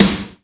HEAVY SN.WAV